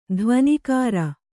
♪ dhvanikāra